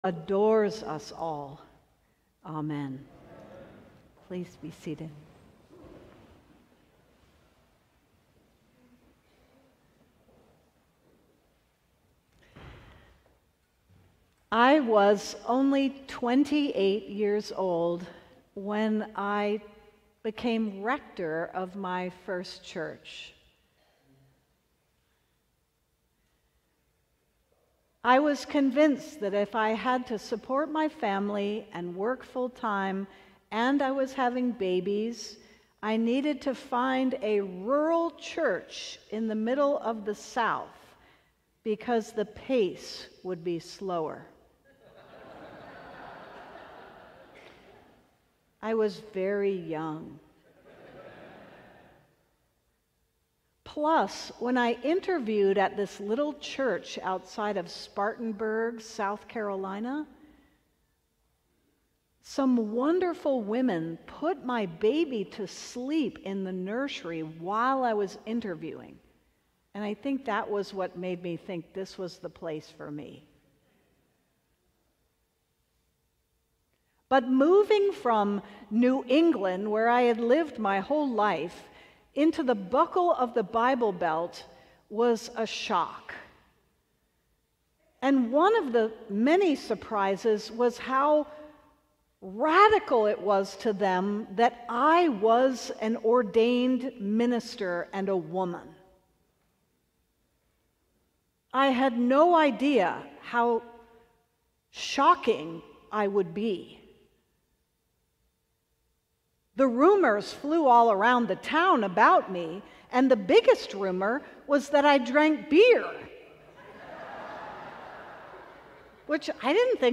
Sermon: Unreasonable Love